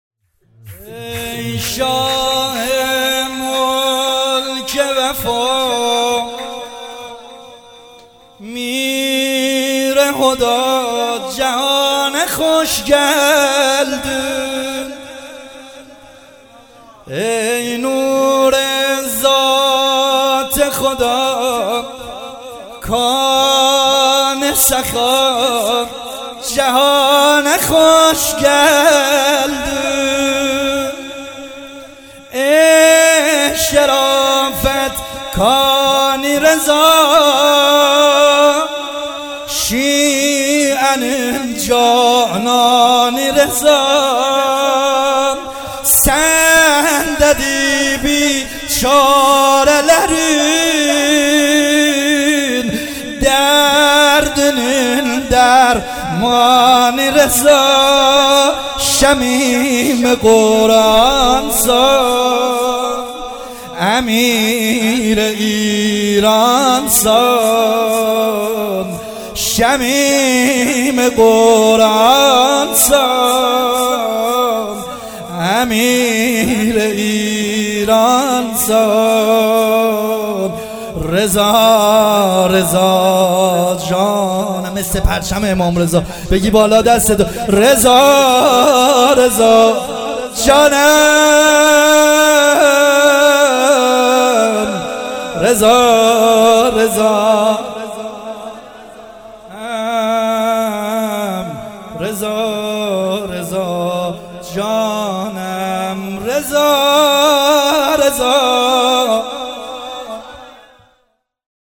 مولودی ترکی